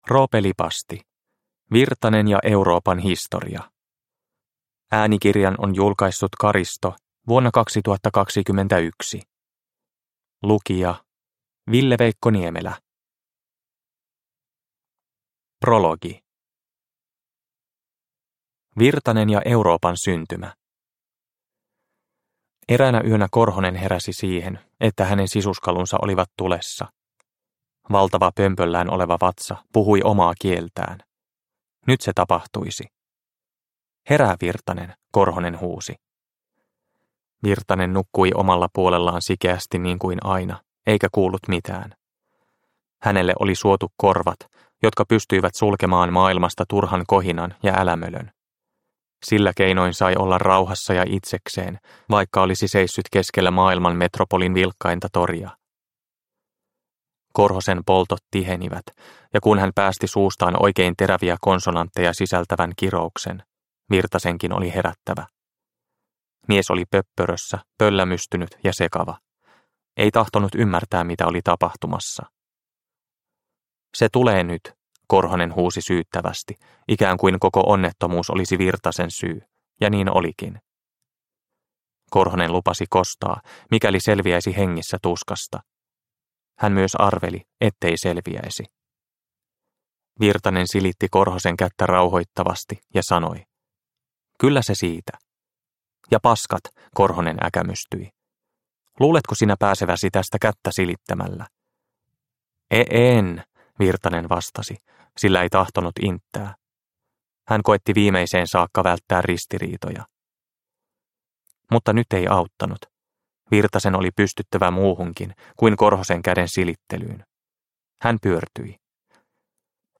Virtanen ja Euroopan historia – Ljudbok